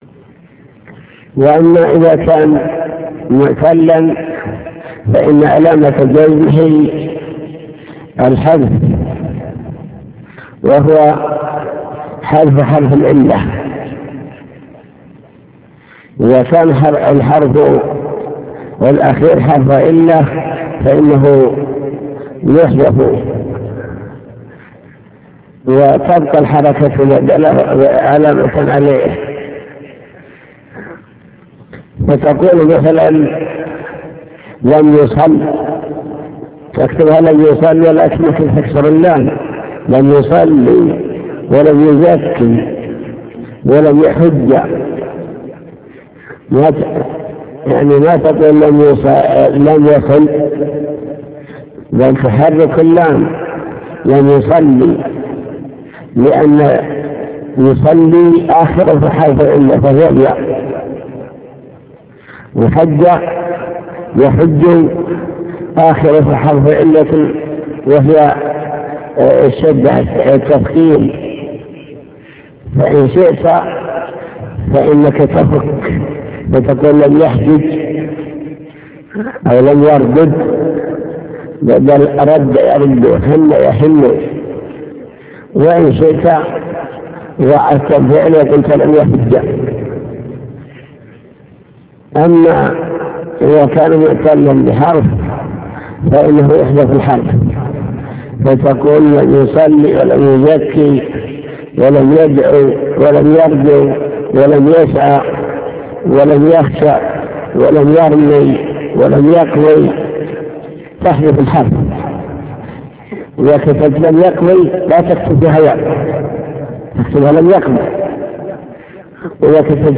المكتبة الصوتية  تسجيلات - كتب  شرح كتاب الآجرومية باب الإعراب أقسام الإعراب الجزم وعلاماته